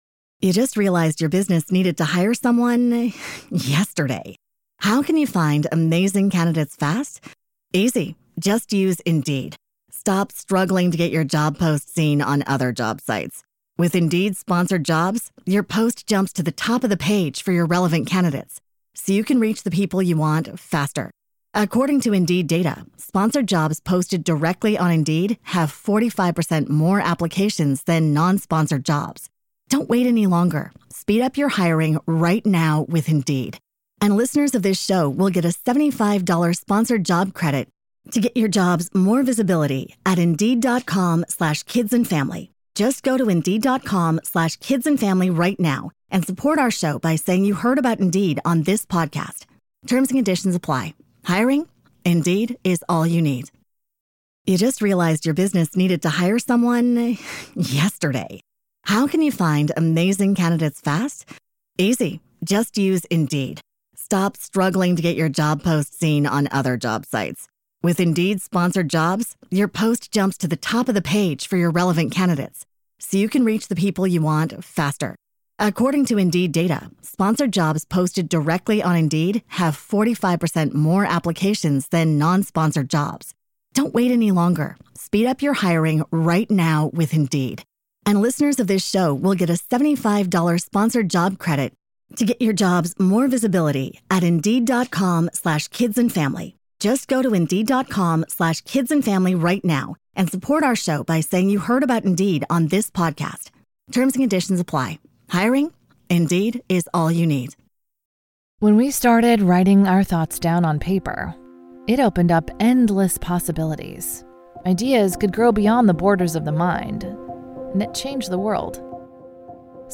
This is an episode of Extraordinary Lives, where we speak to people with a unique life story to tell.